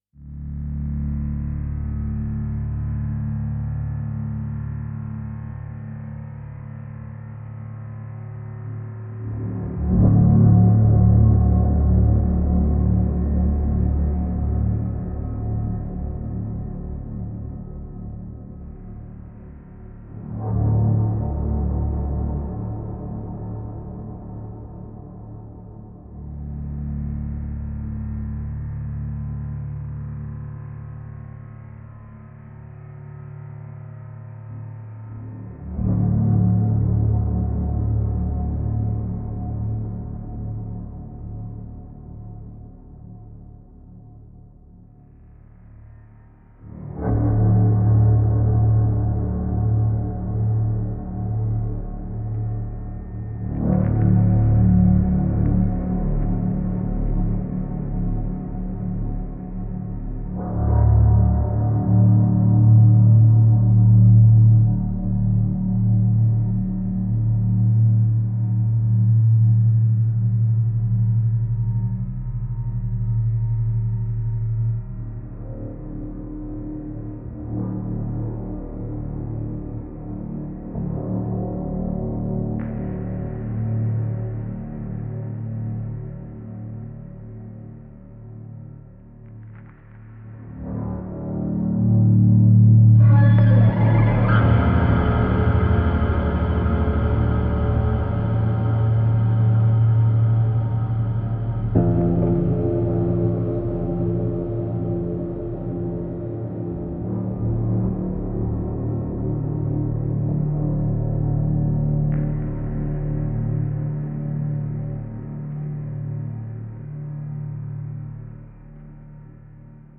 Waves of dark synth swells form a spine-tingling ambience.